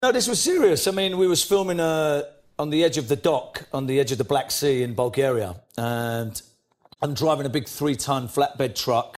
纯正的英式英语配音
精准英式发音的专业旁白
文本转语音
标准英音
自然韵律